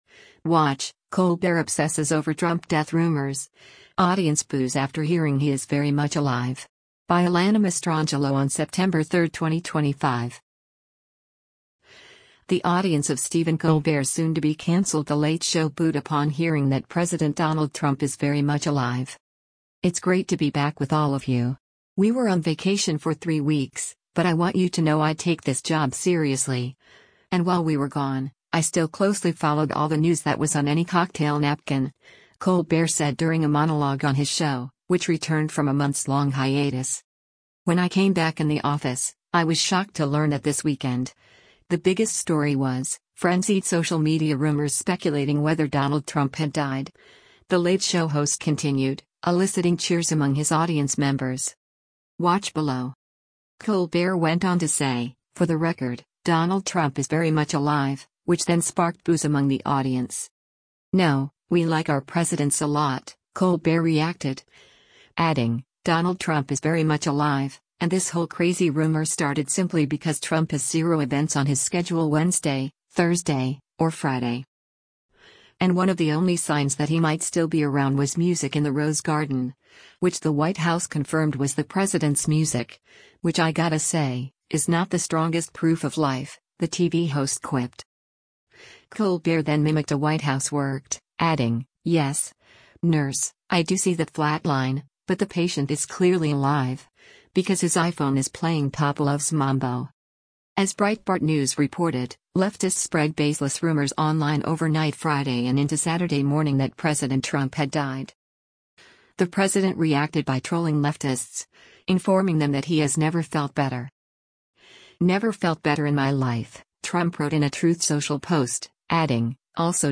Watch: Colbert Obsesses over Trump Death Rumors, Audience Boos After Hearing He 'Is Very Much Alive'
The audience of Stephen Colbert’s soon-to-be-canceled The Late Show booed upon hearing that President Donald Trump “is very much alive.”
“When I came back in the office, I was shocked to learn that this weekend, the biggest story was, ‘Frenzied social media rumors speculating whether Donald Trump had died,'” The Late Show host continued, eliciting cheers among his audience members.
Colbert went on to say, “For the record, Donald Trump is very much alive,” which then sparked boos among the audience.